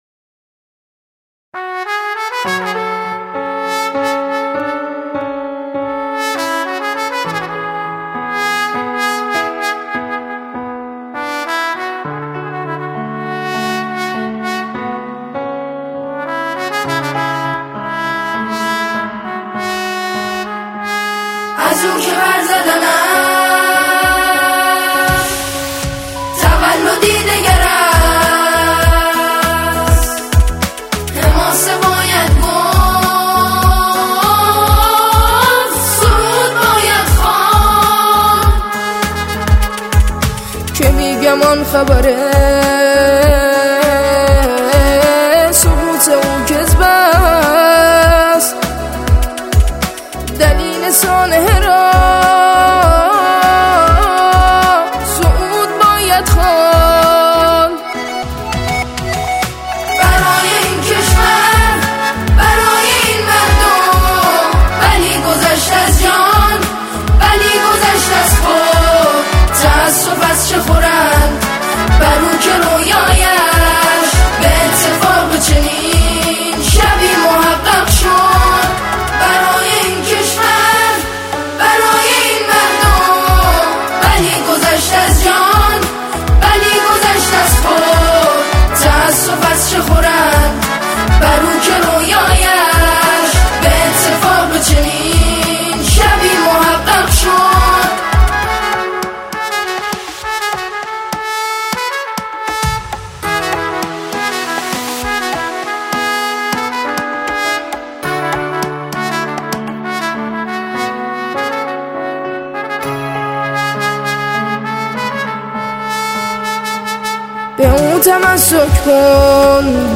سرود شهید رئیسی